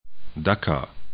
Pronunciation
'daka